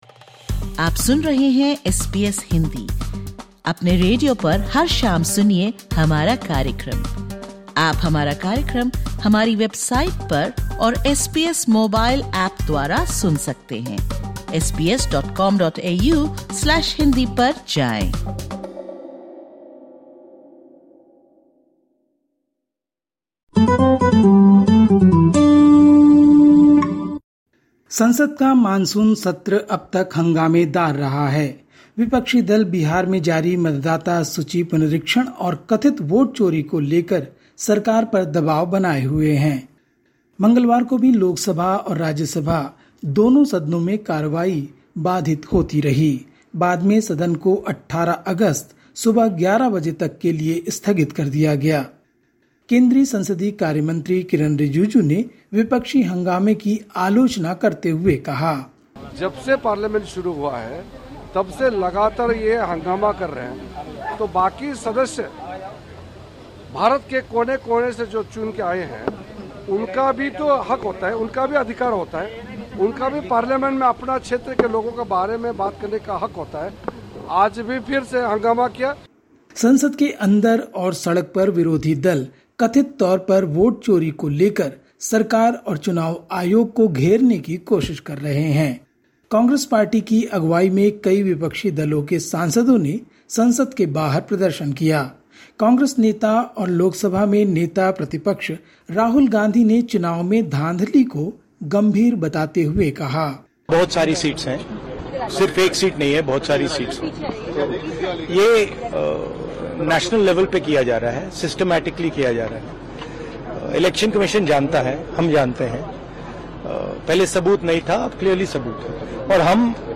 Listen to the latest SBS Hindi news from India. 13/08/25